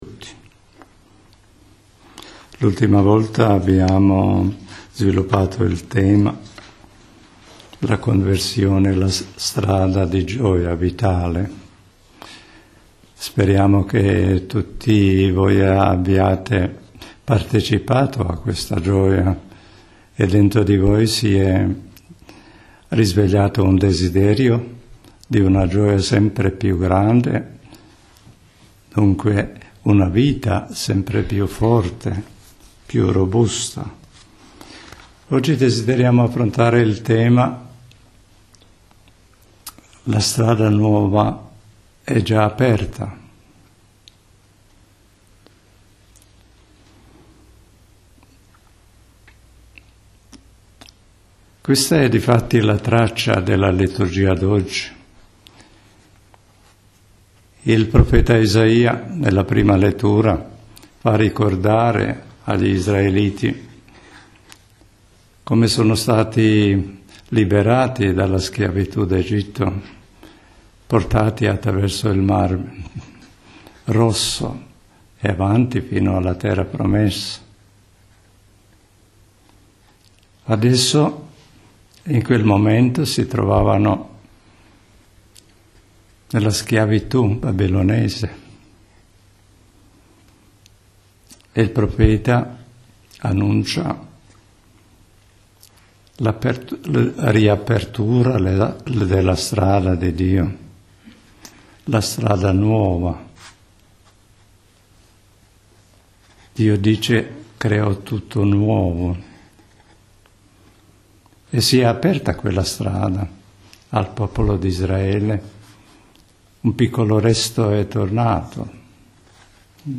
Riflessione – La strada nuova è già aperta – 06.04.2019